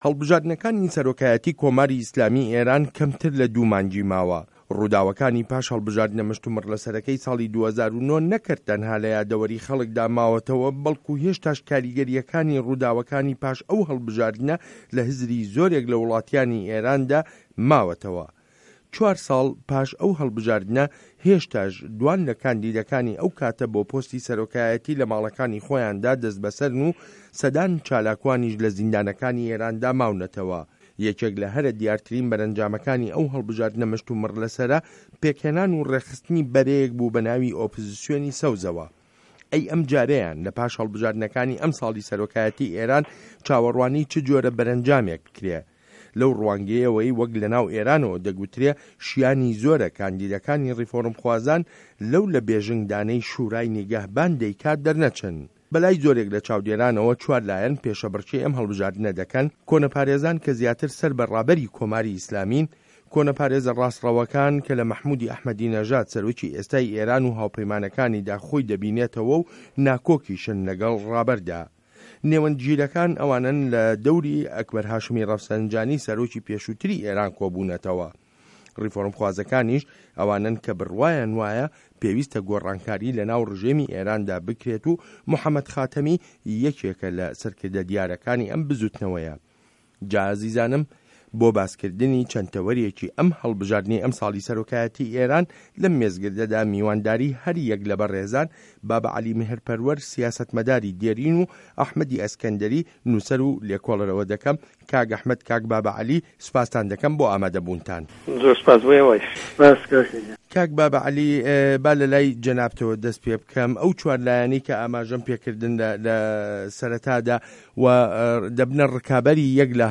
مێزگرد: کێ سه‌رۆکی داهاتووی ئێرانه